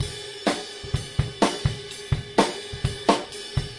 Ludwig Supraphonic " supra beat straight crashride snare
描述：一个带有碰撞/骑行的流行/摇滚鼓点。使用Ludwig Supraphonic。